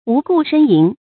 無故呻吟 注音： ㄨˊ ㄍㄨˋ ㄕㄣ ㄧㄣˊ 讀音讀法： 意思解釋： 猶言無病呻吟。